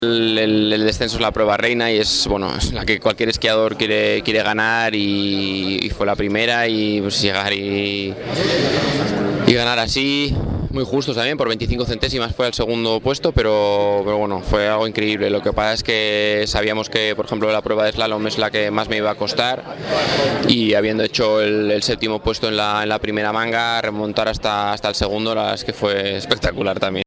Y describía con gran alegría y aún con cierto toque de incredulidad